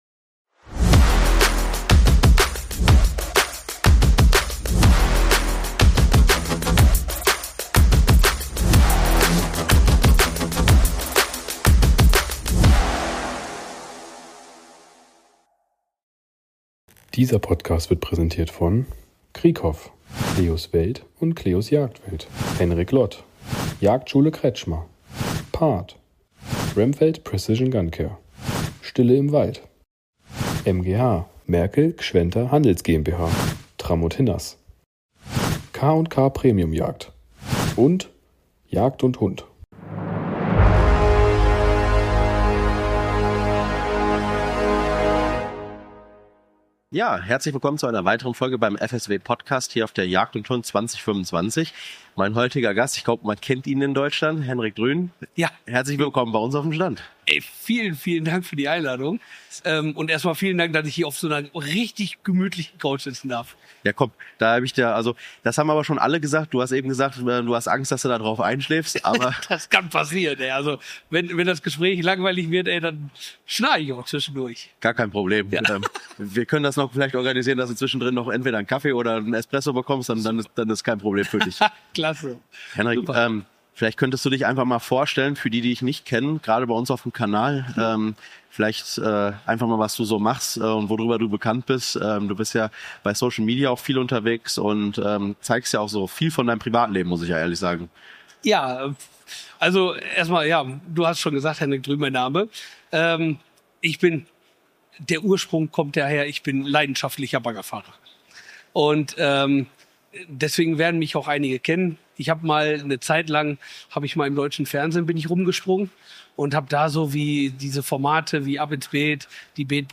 Willkommen auf der Jagd & Hund 2025! Wir sind live auf Europas größter Jagdmesse unterwegs und sprechen mit spannenden Gästen aus der Jagdszene.